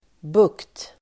Uttal: [buk:t]